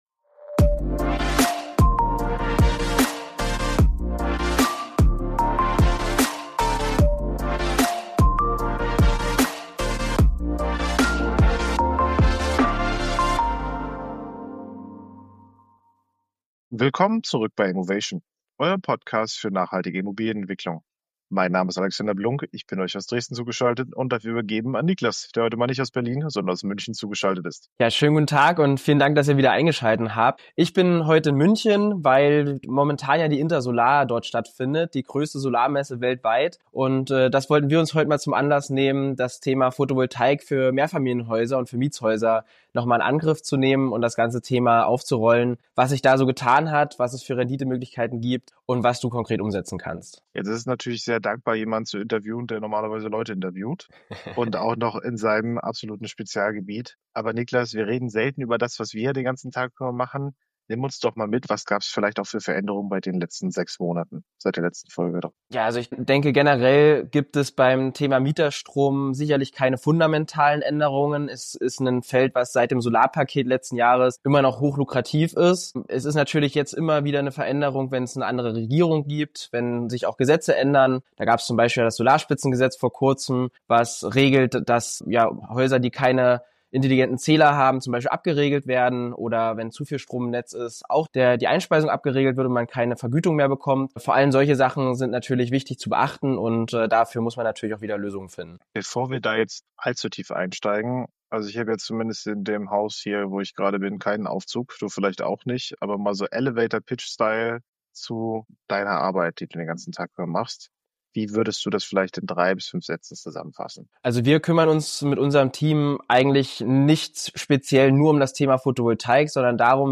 berichtet direkt von der Intersolar in München